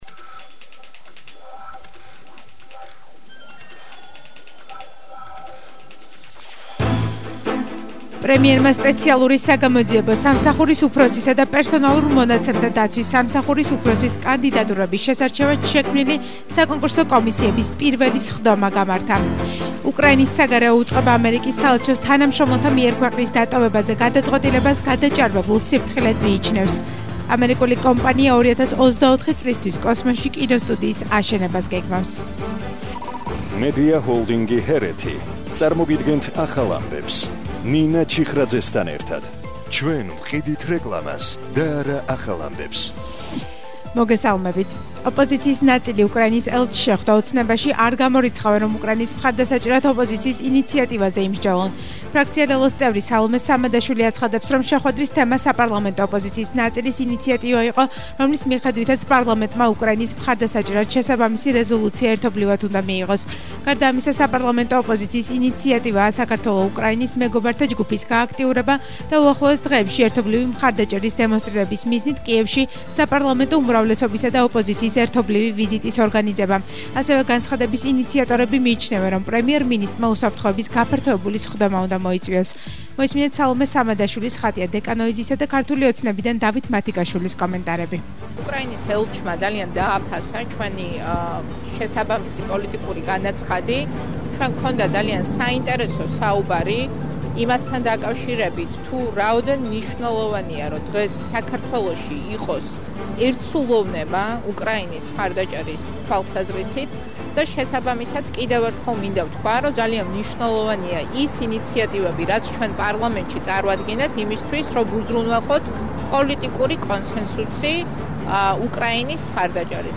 ახალი ამბები 17:00 საათზე – 24/01/22 - HeretiFM